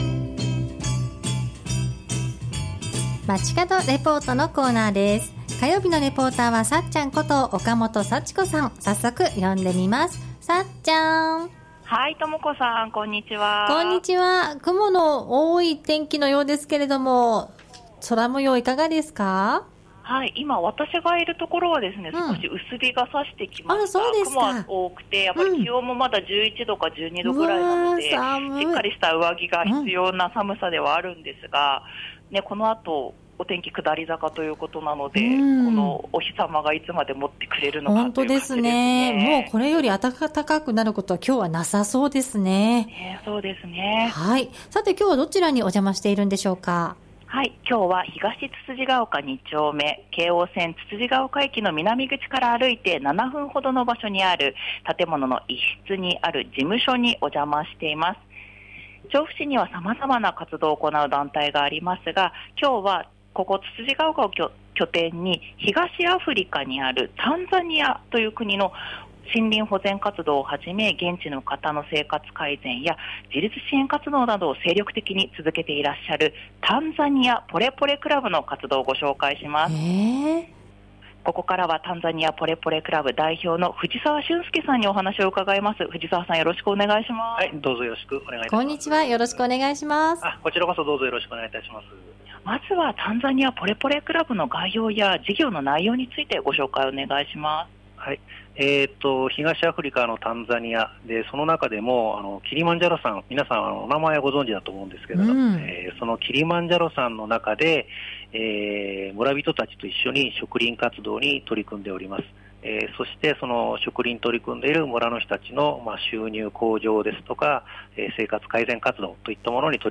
中継は京王線・つつじヶ丘駅南口 徒歩7分の場所にある「タンザニア・ポレポレクラブ」の事務局からお届けしました。